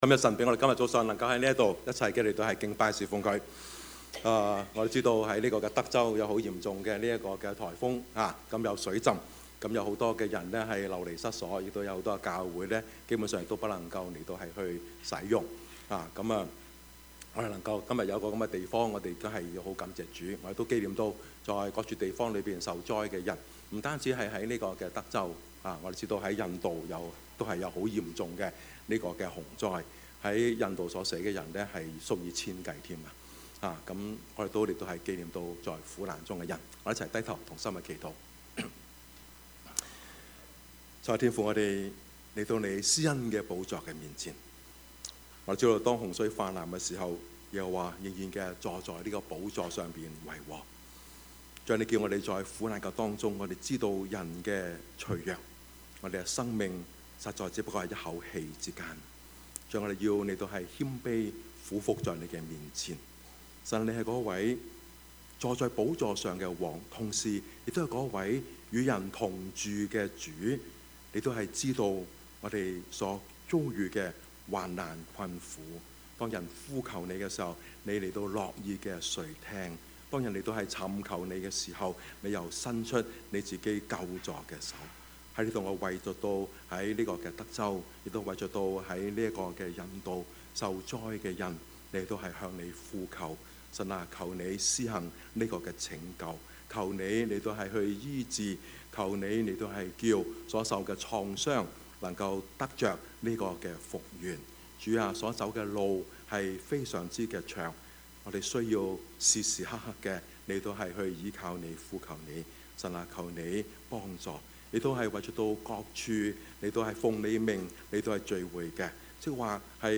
Service Type: 主日崇拜
Topics: 主日證道 « 蒙福的人 孫中山先生(四) »